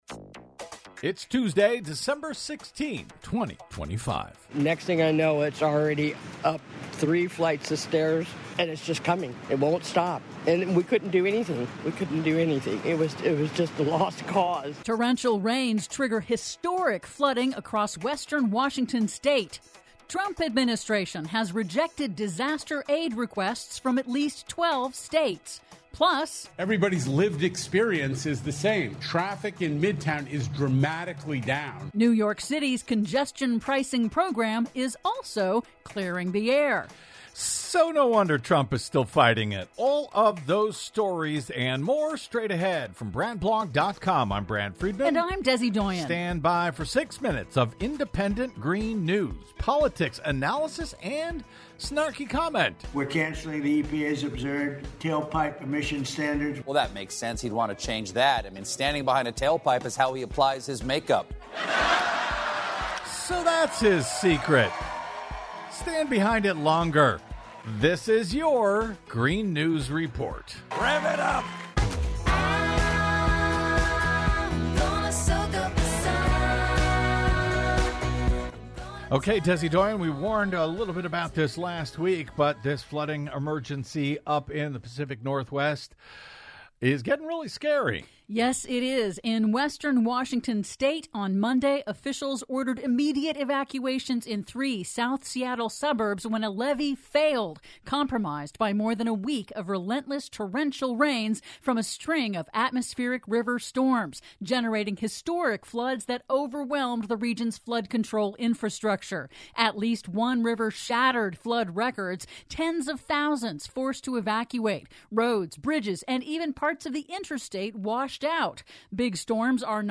IN TODAY'S RADIO REPORT: Torrential rains trigger historic flooding across western Washington State; Trump Administration has rejected disaster aid requests from at least 12 states; PLUS: NYC's congestion pricing program has dramatically reduced traffic and air pollution... All that and more in today's Green News Report!